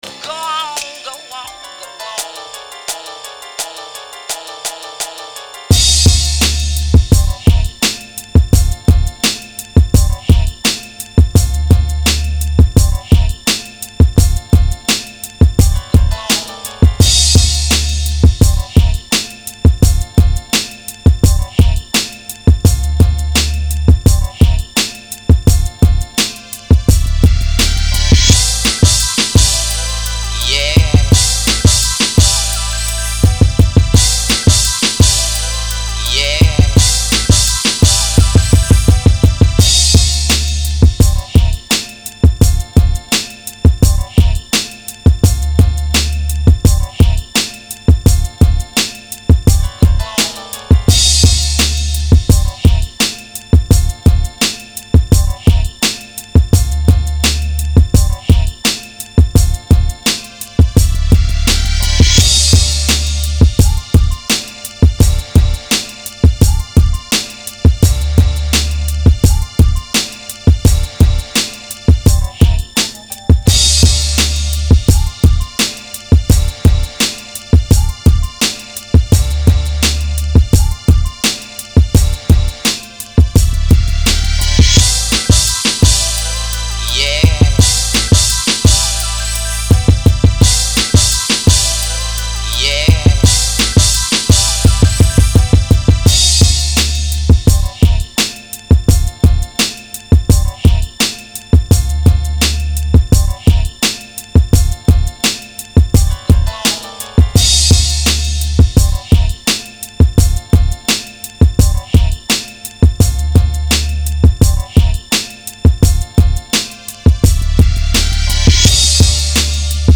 instrumental project